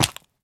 Minecraft Version Minecraft Version latest Latest Release | Latest Snapshot latest / assets / minecraft / sounds / mob / turtle / egg / jump_egg3.ogg Compare With Compare With Latest Release | Latest Snapshot
jump_egg3.ogg